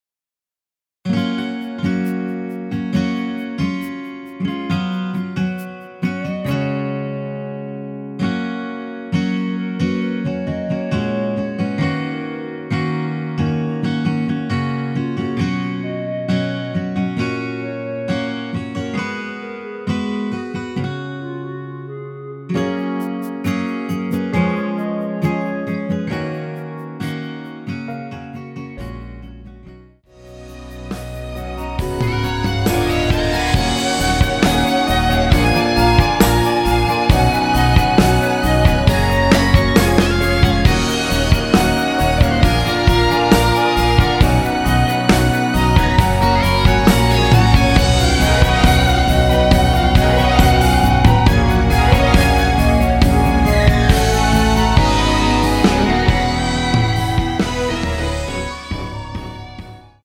원키에서(+5)올린 멜로디 포함된 MR입니다.
F#
앞부분30초, 뒷부분30초씩 편집해서 올려 드리고 있습니다.
중간에 음이 끈어지고 다시 나오는 이유는